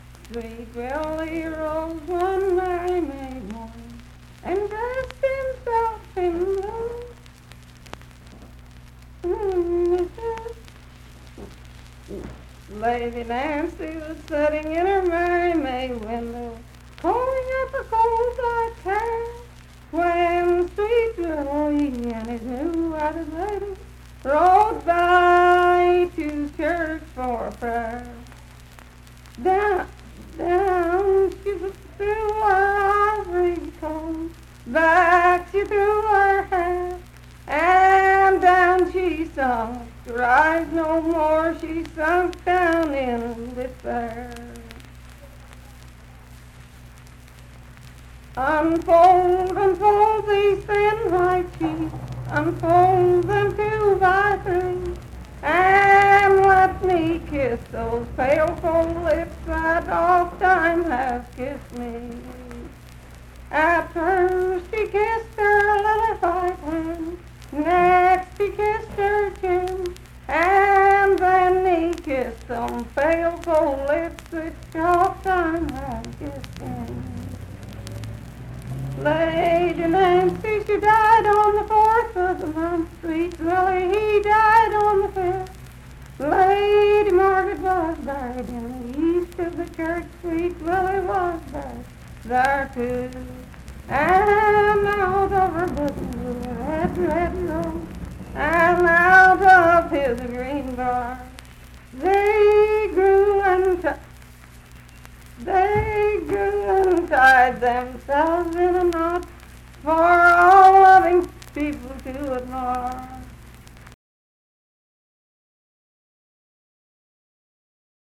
Unaccompanied vocal performance
Verse-refrain 7(4).
Voice (sung)